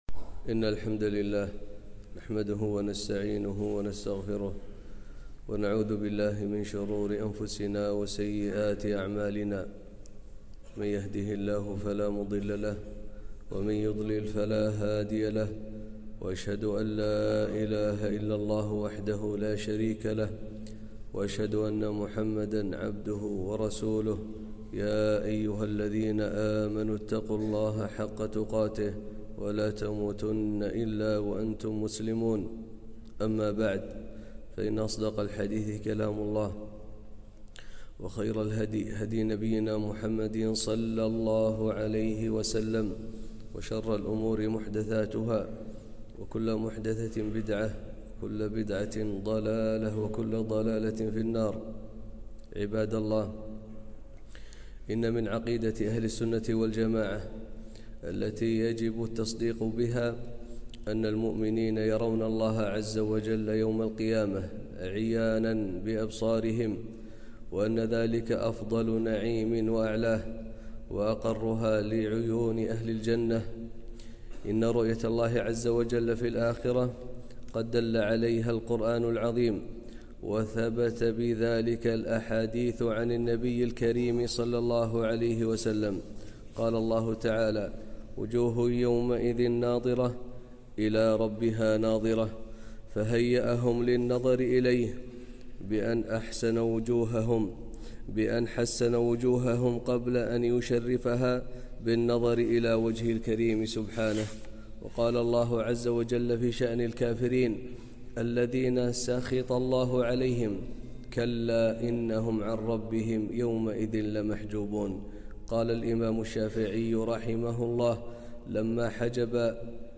خطبة - رؤية الله عز وجل في الجنة